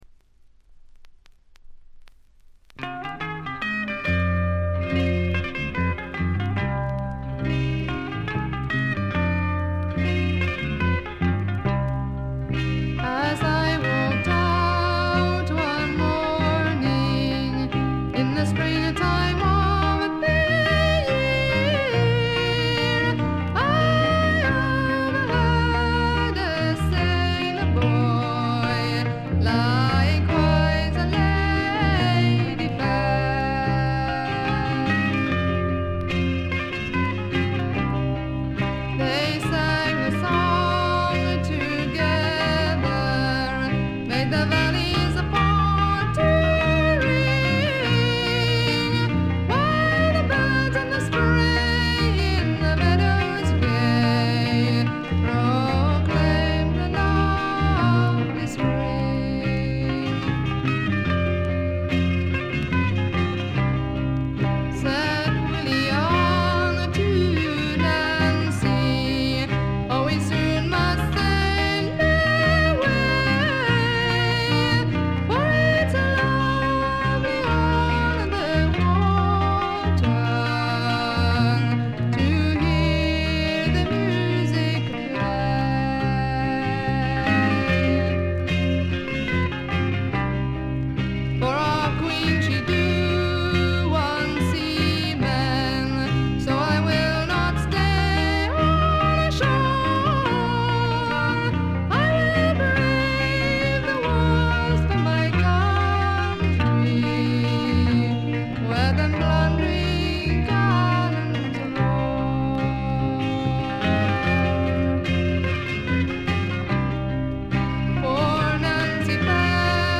これ以外は静音部で軽微なチリプチ、バックグラウンドノイズ。
英国エレクトリック・フォーク最高峰の一枚。
試聴曲は現品からの取り込み音源です。